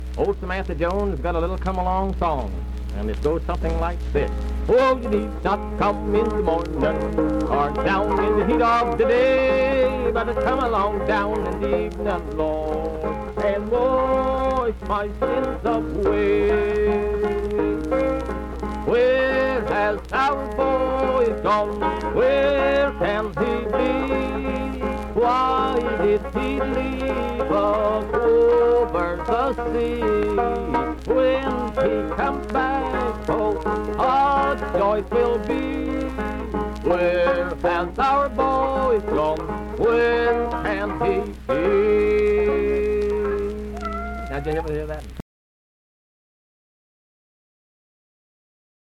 Vocal performance accompanied by banjo.
Miscellaneous--Musical
Voice (sung), Banjo
Wood County (W. Va.), Vienna (W. Va.)